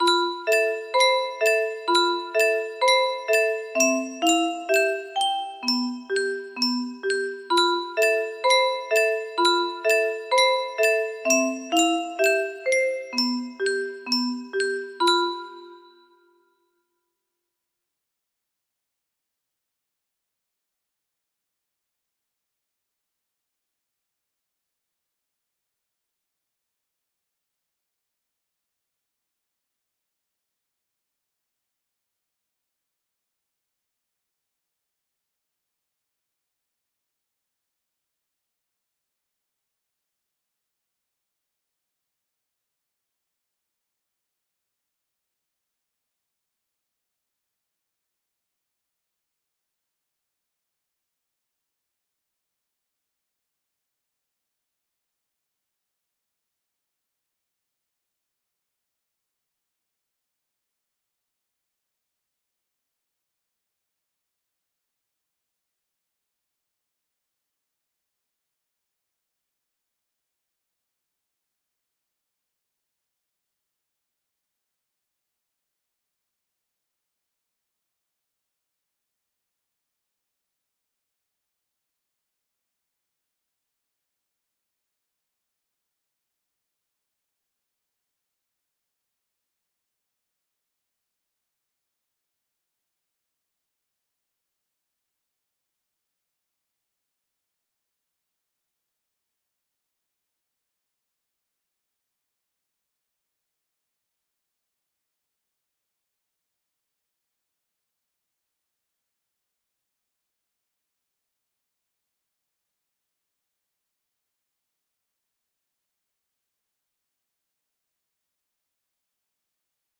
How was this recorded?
Imported from MIDI$MUSICBOX.mid